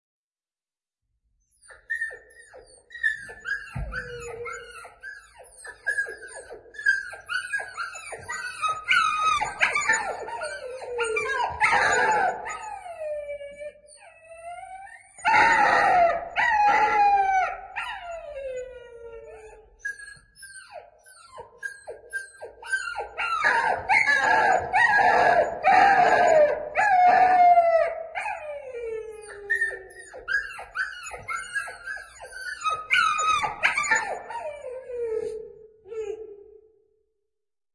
描述：克隆的小狗，有过量的回响扰乱。
Tag: 汪汪地叫 狗吠声 狼嚎 小狗 树皮 请求 小狗